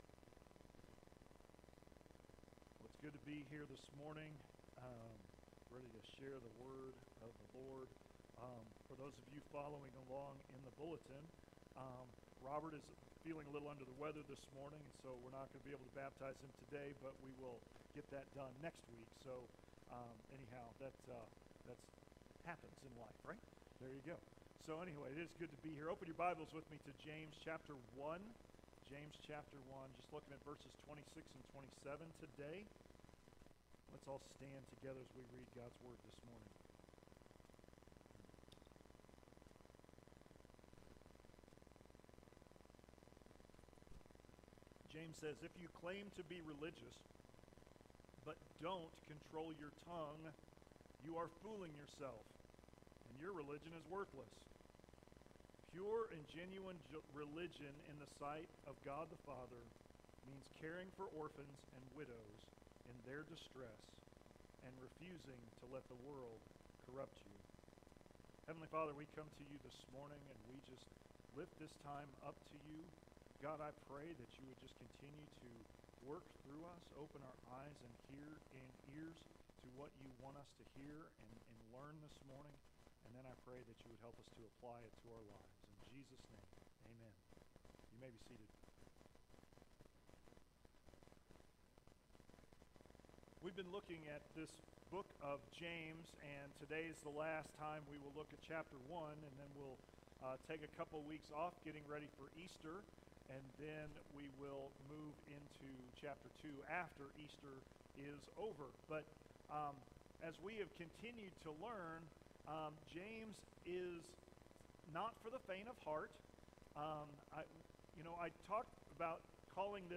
Sermons | Centennial Baptist Church